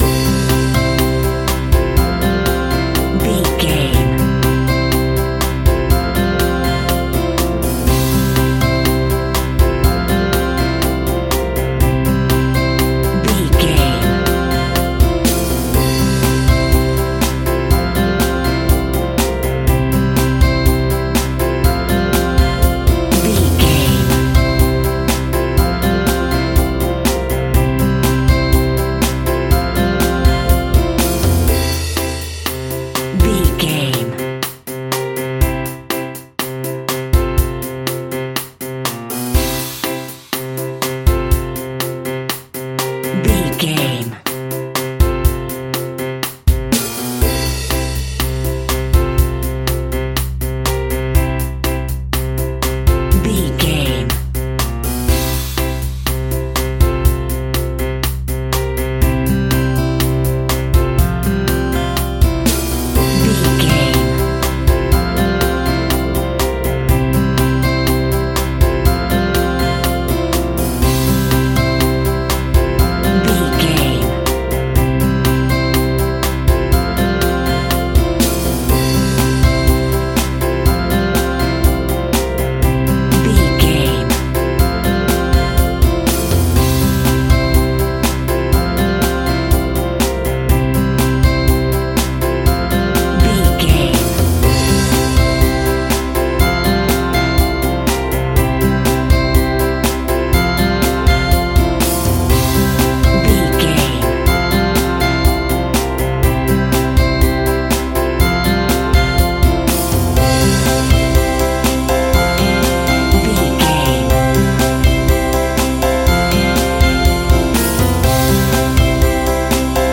Ionian/Major
electronic
techno
trance
synths
drone
glitch
synth lead
synth bass
synth drums